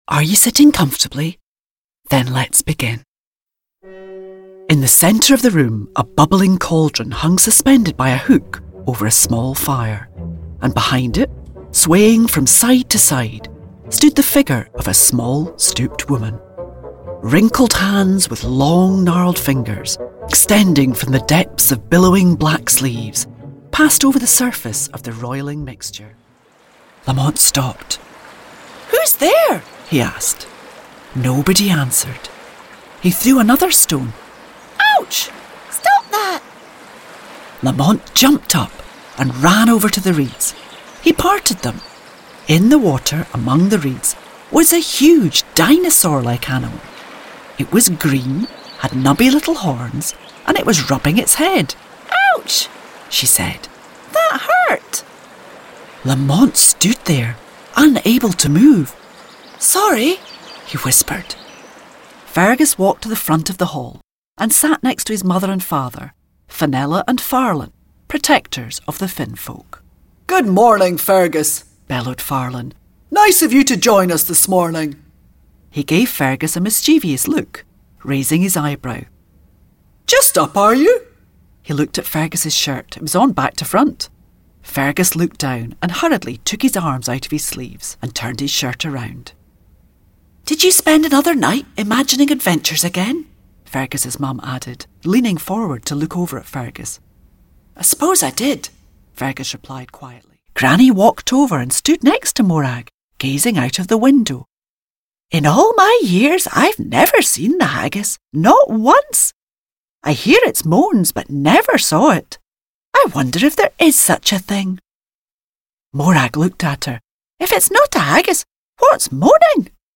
Scottish voice, warm, commanding, authoritative, versatile actress.
schottisch
Sprechprobe: Sonstiges (Muttersprache):
A trustworthy, warm delivery is assured.